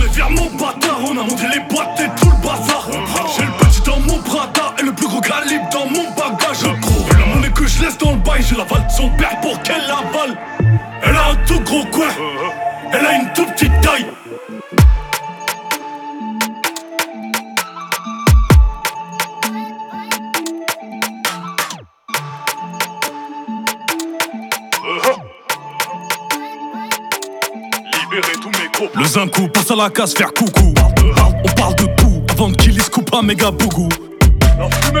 Жанр: Иностранный рэп и хип-хоп / Рэп и хип-хоп